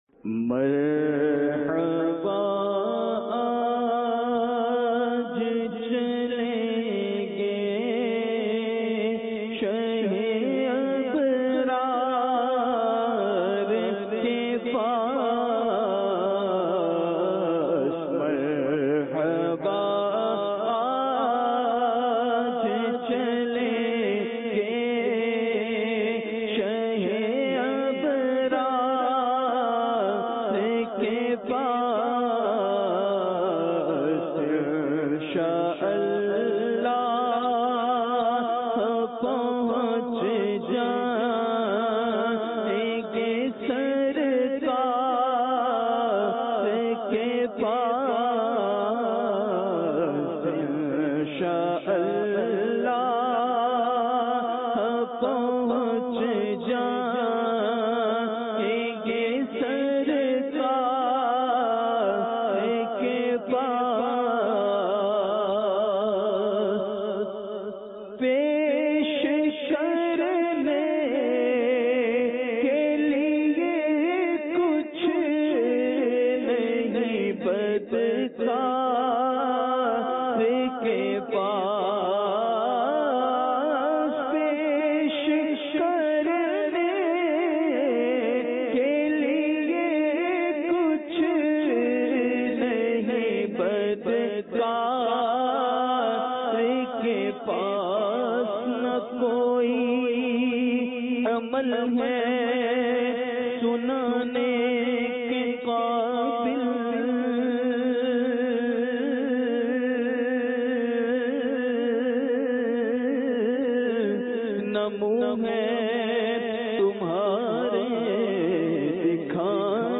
اجتماعِ ذکرونعت میں پڑھا جانے والا
سوزبھری طرز اور خوبصورت آواز میں